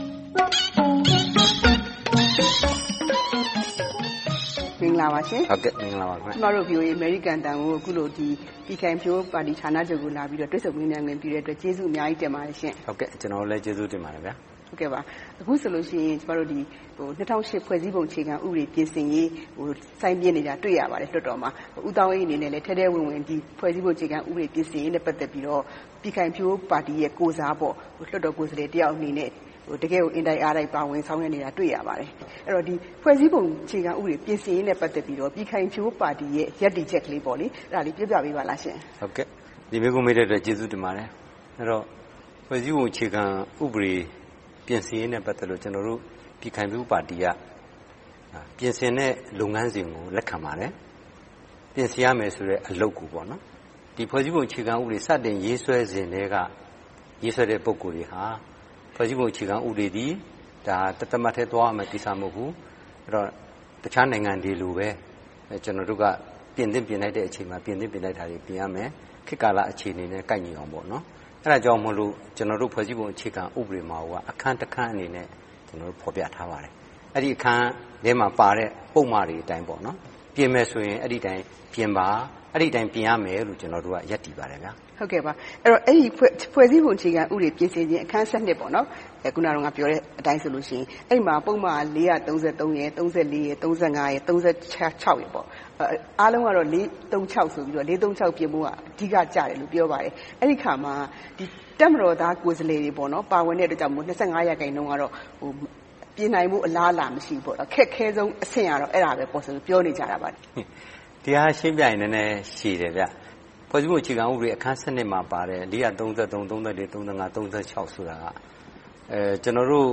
သီးသန့်တွေ့ဆုံမေးမြန်းထားပါတယ်။